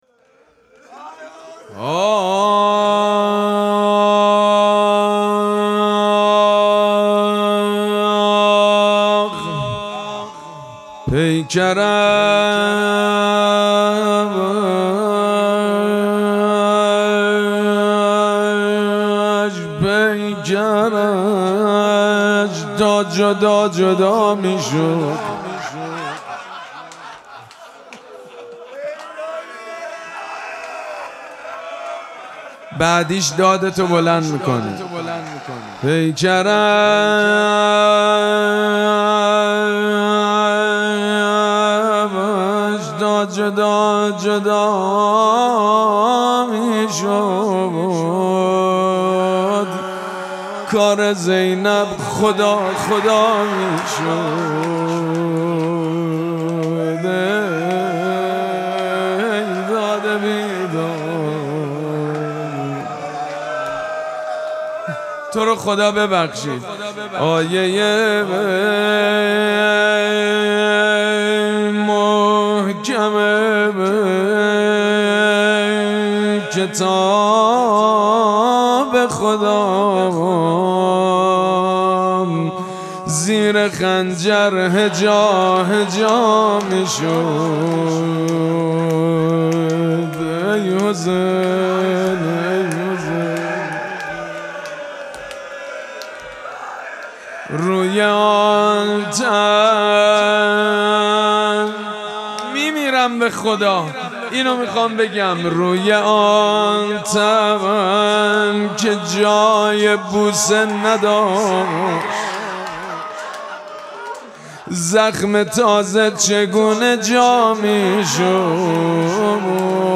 مراسم مناجات شب پنجم ماه مبارک رمضان
روضه
مداح
حاج سید مجید بنی فاطمه